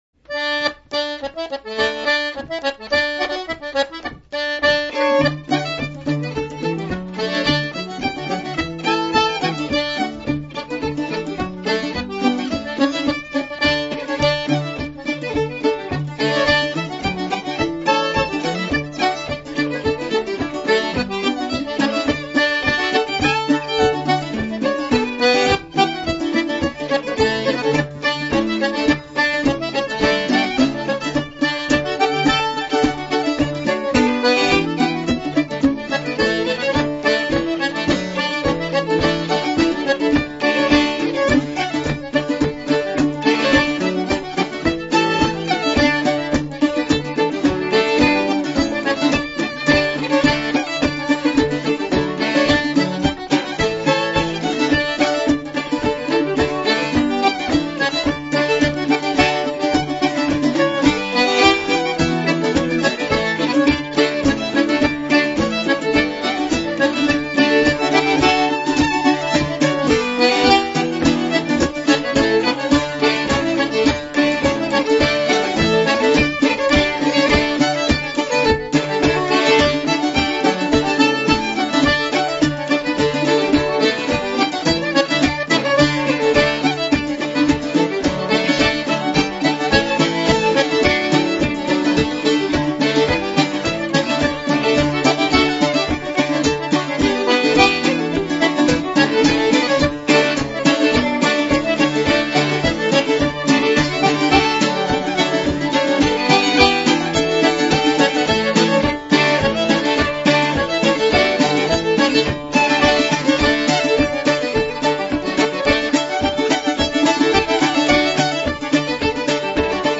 Below are links to mp3s of the FSC Country Dancing music as heard and used on camp.